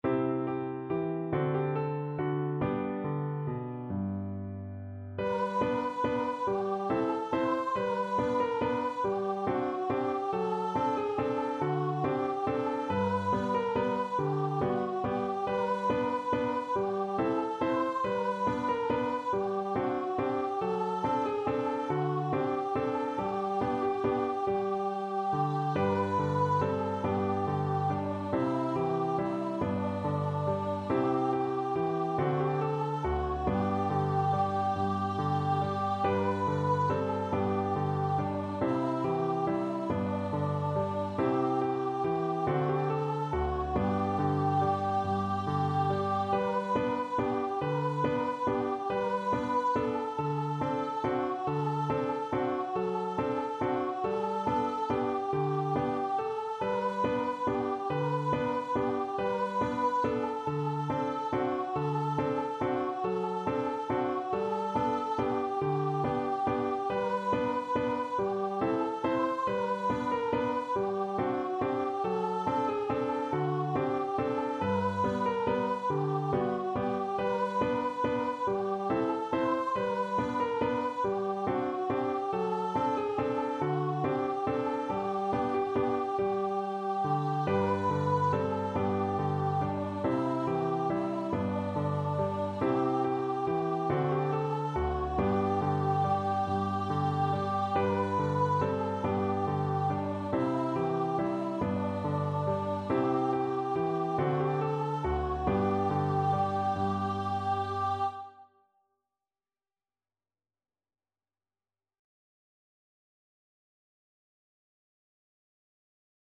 Voice
G major (Sounding Pitch) (View more G major Music for Voice )
6/8 (View more 6/8 Music)
Steadily = 140
Traditional (View more Traditional Voice Music)